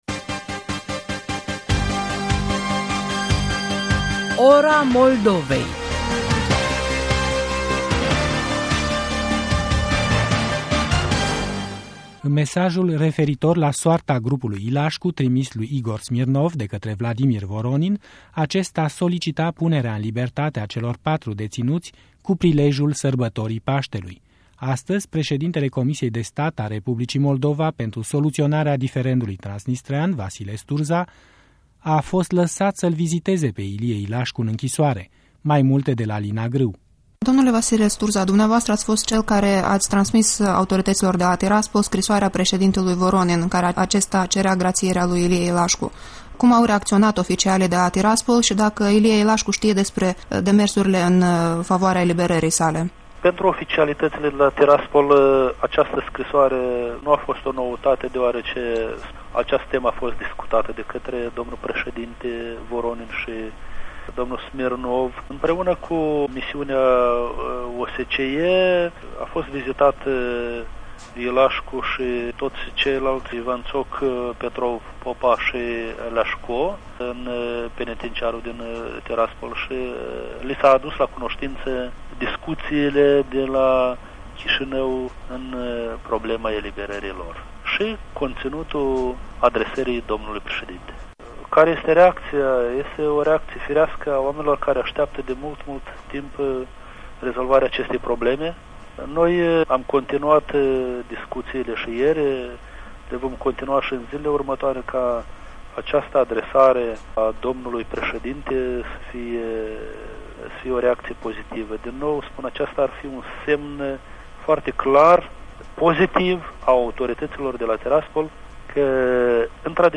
Un interviu cu Vasile Sturza despre cazul Ilașcu